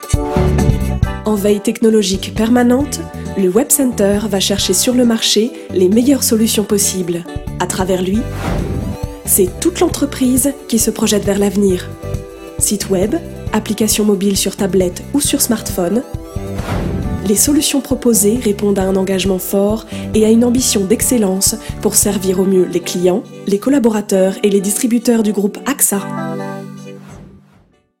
Voix off
Film institutionnel (dynamique)